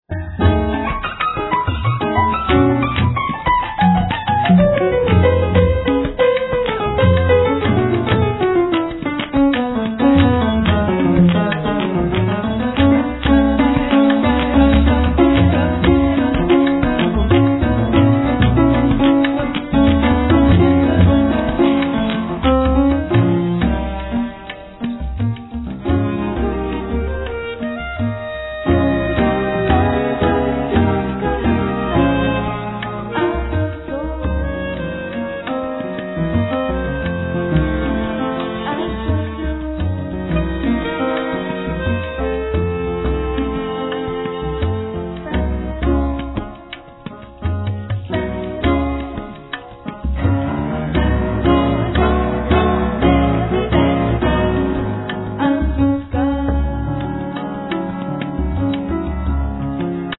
percussion
piano
winds
cello, piano
ethnic winds
composition, piano, voice, acordion, berimbao